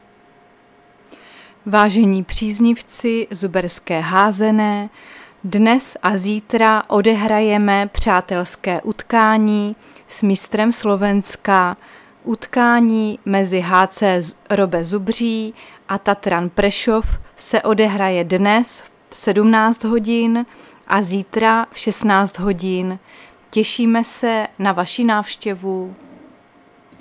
Záznam hlášení místního rozhlasu 22.8.2024